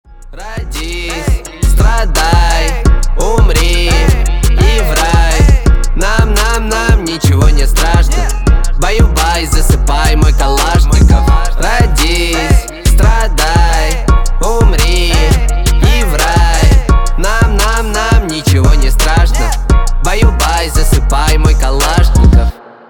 Рэп и Хип Хоп
весёлые